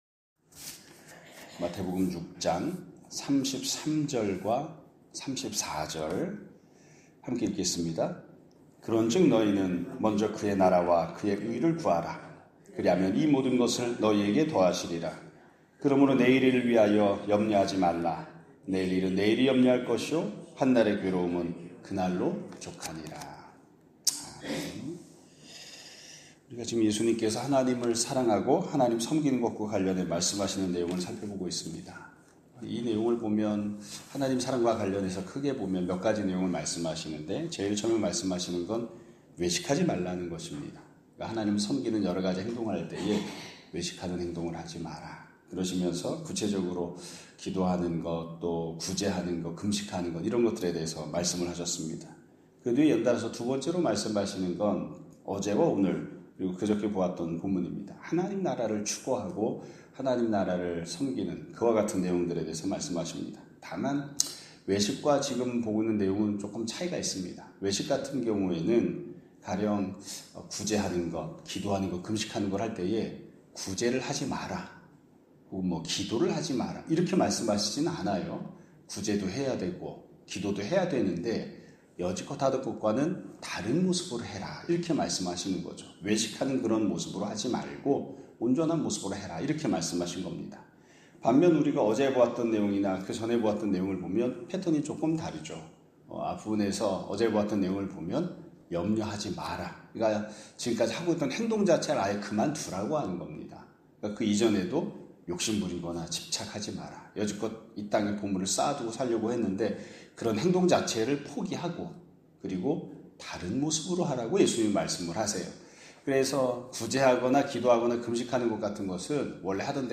2025년 6월 20일(금요일) <아침예배> 설교입니다.